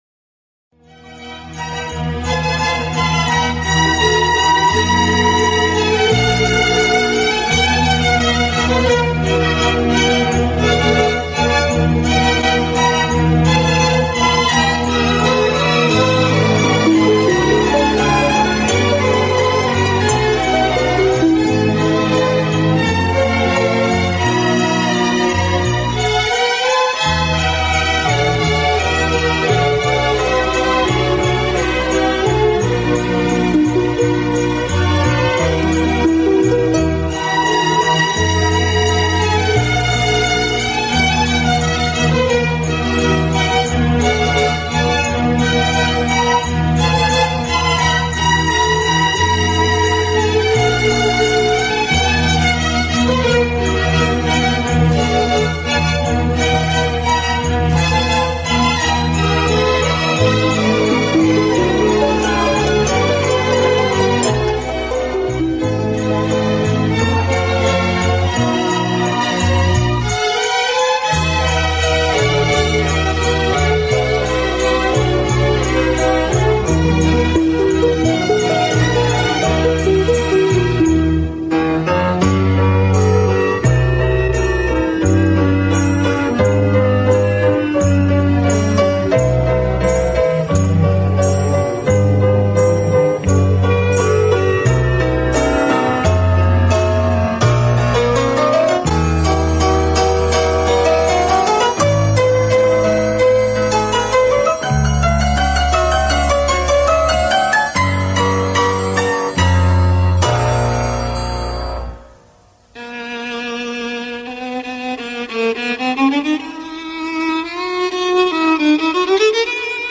звучащую фоном во втором фрагменте(Диктор оркестр не обьявил)...